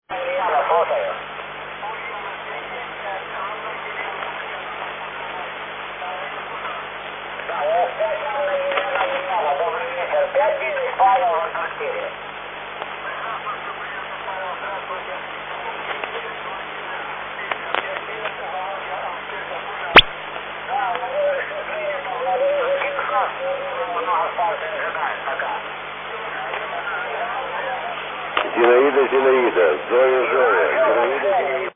Here you can hear one of his SSB QSOs (in Russian) from Ukrainian Antarctic Station.